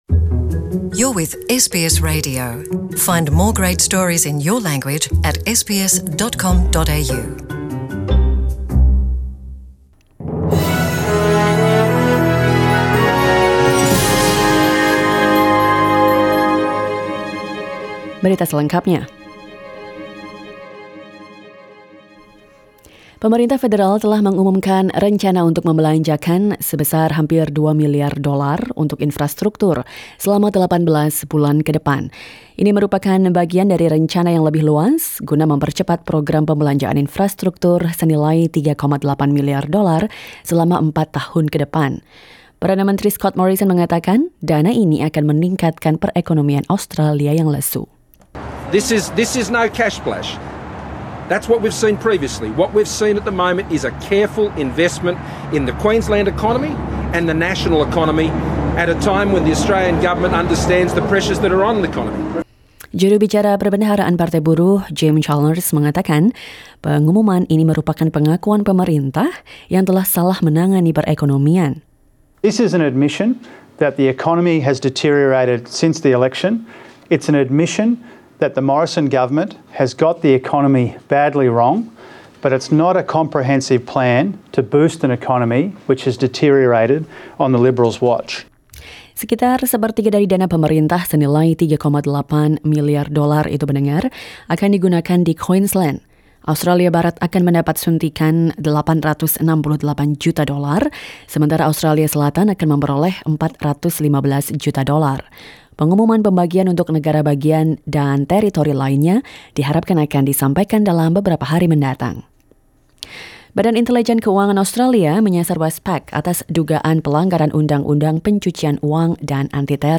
SBS Radio news in Indonesian, 20 November 2019.